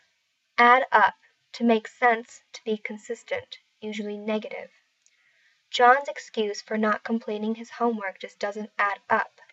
英語ネイティブによる発音は以下のリンクをクリックしてください。